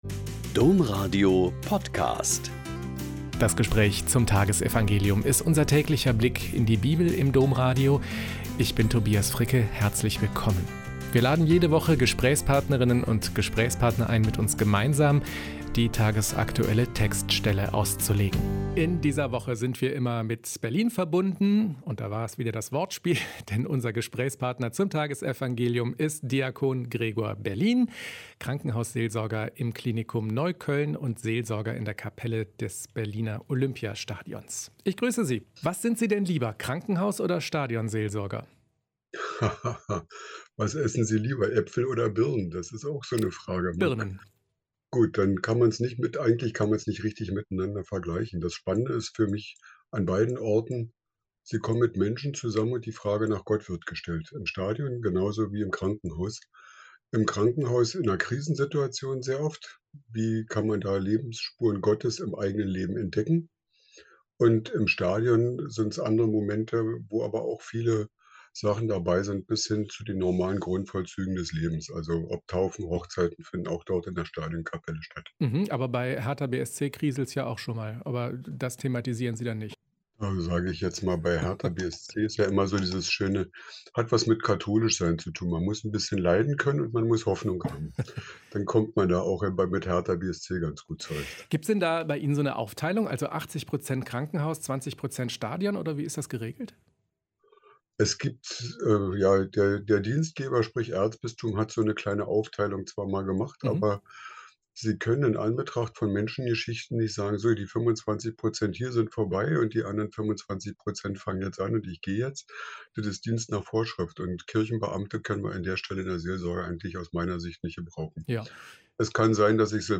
Lk 10,21-24 - Gespräch